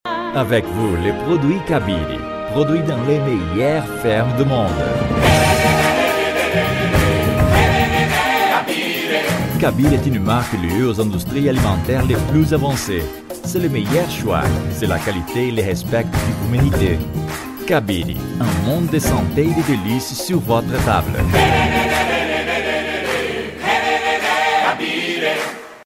Locução em francês para vídeo da empresa Cabire.